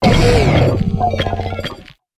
Grito de Ferropalmas.ogg
Grito_de_Ferropalmas.ogg